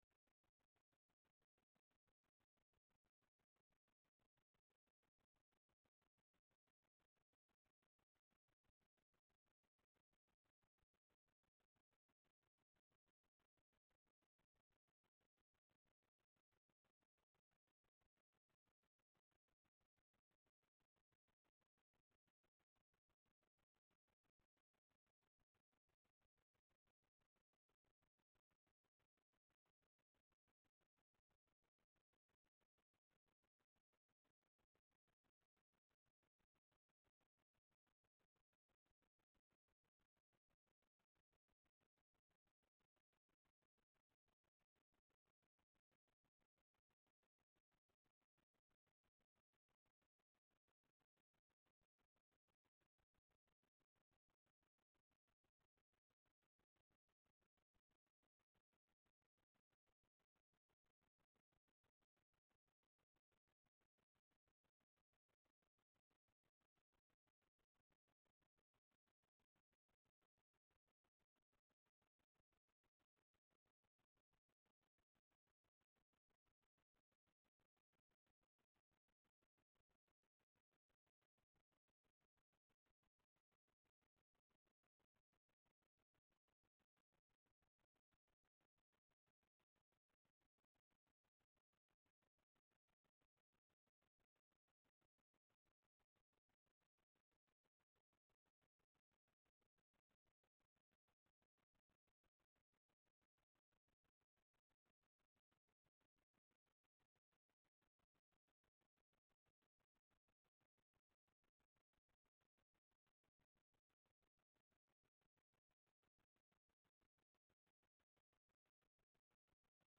[sermon] Philippians 2:3-8 How Low Do You Go?